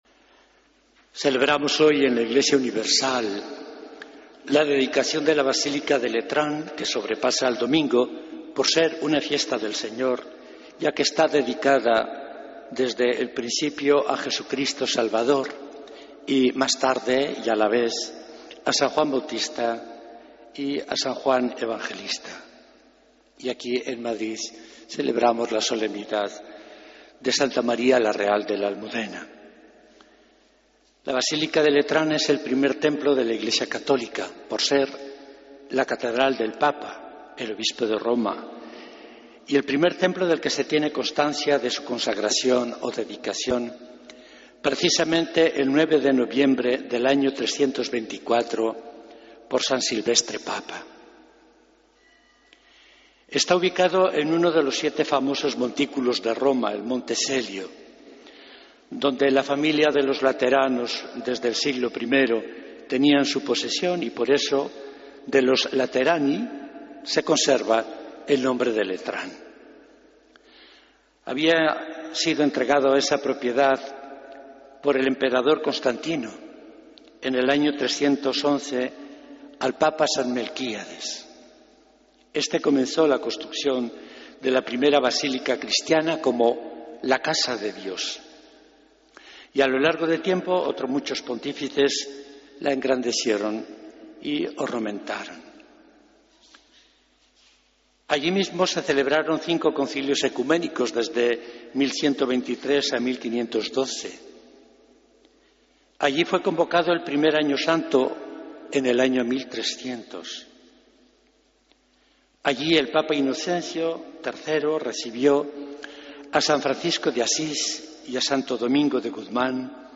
Homilía del Domingo 9 de Noviembre de 2014